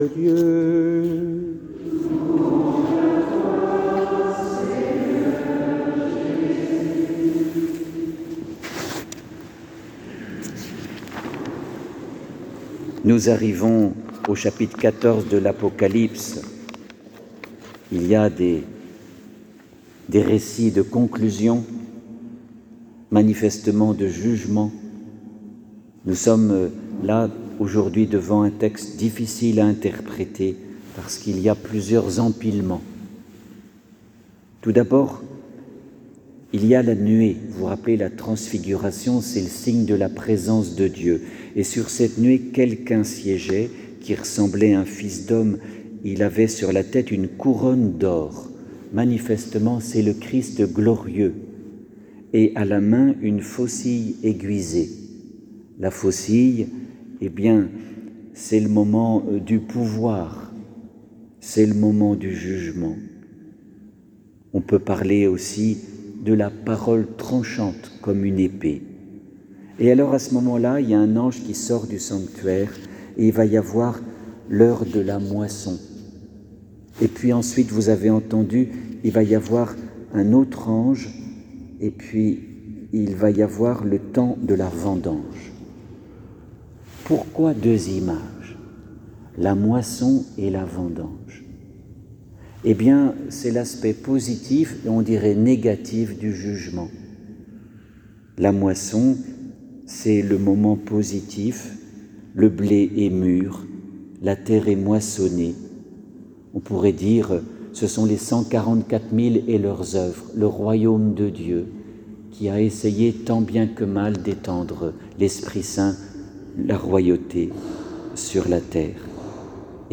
les homélies « Préparons notre Résurrection » – Eglise Saint Ignace